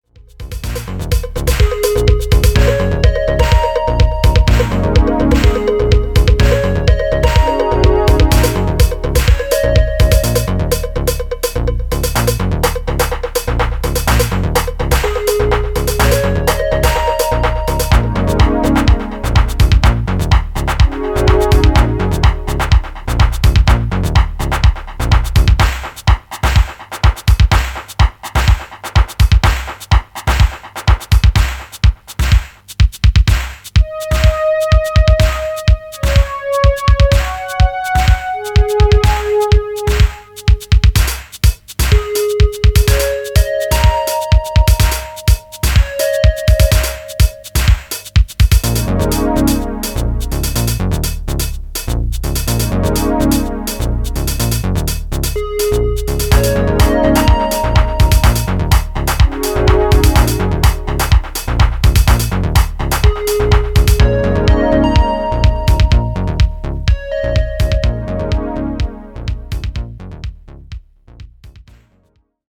vintage sound, designed by a modern mind.